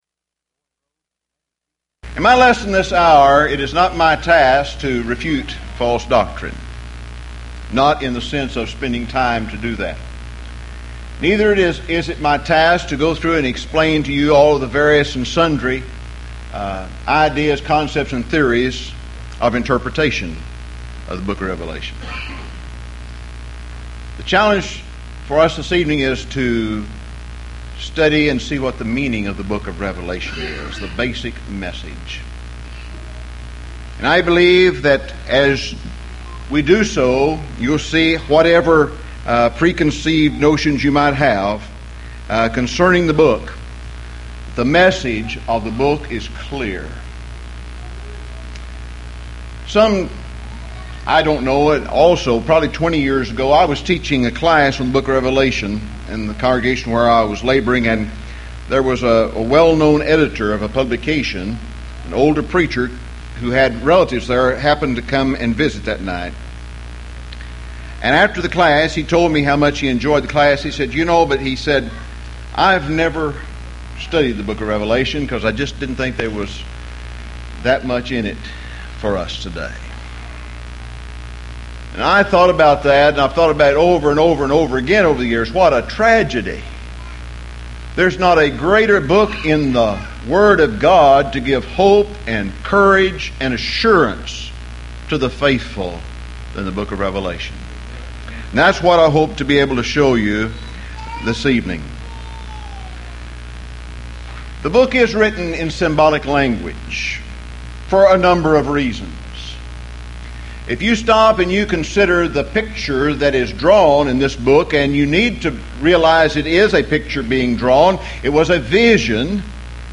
Series: Houston College of the Bible Lectures Event: 1997 HCB Lectures Theme/Title: Premillennialism